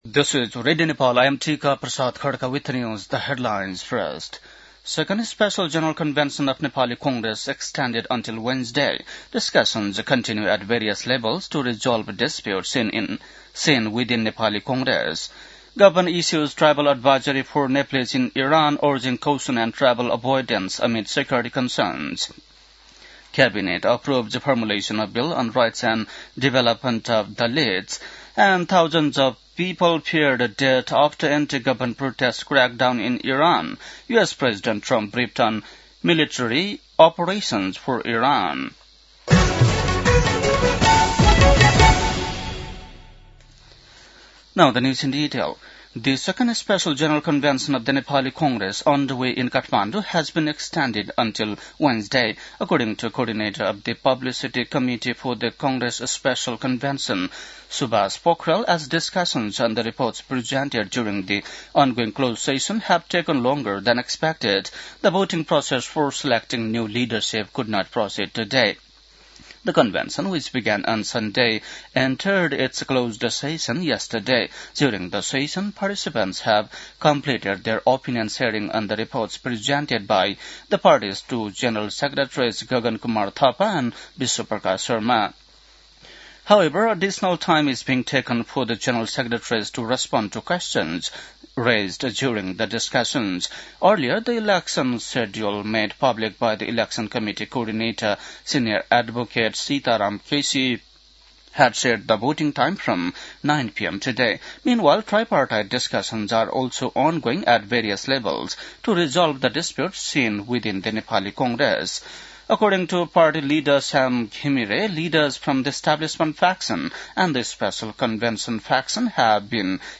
बेलुकी ८ बजेको अङ्ग्रेजी समाचार : २९ पुष , २०८२
8-pm-english-news-9-29.mp3